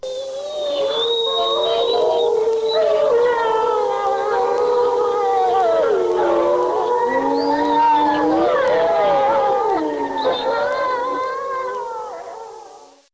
Tím se dorozumíváme ve smečce i mezi smečkami.
Každý vlk má vlastní způsob vytí.
vlk.mp3